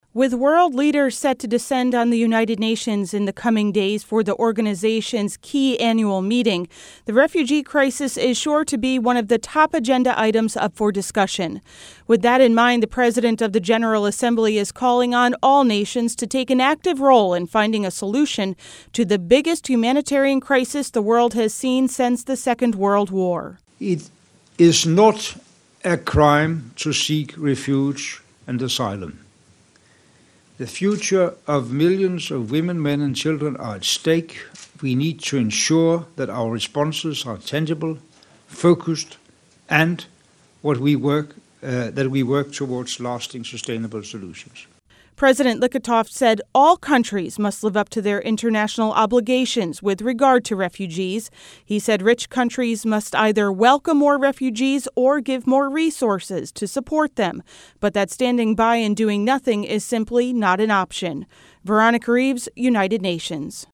NEWS-PGA-PRESSCONFERENCE-21SEP15.mp3